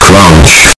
Play Ear R**e CRUNCH!!! soundboard button | Soundboardly
ear-rape-crunch.mp3